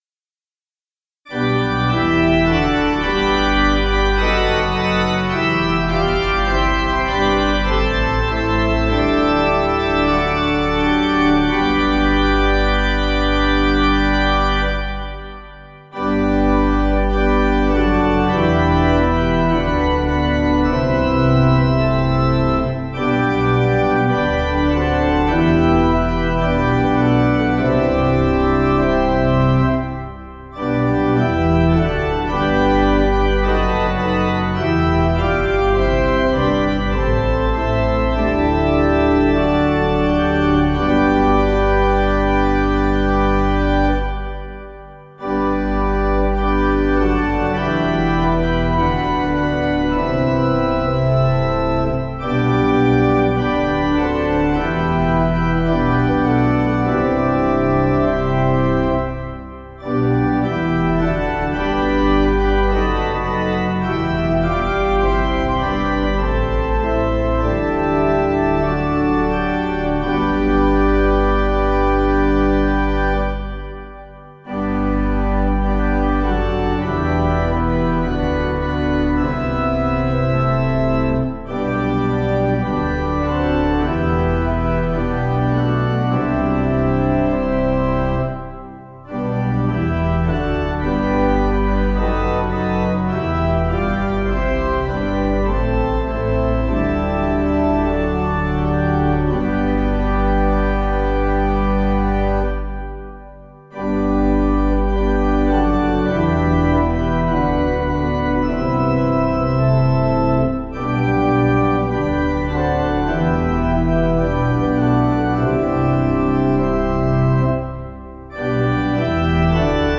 Tune: CATHCART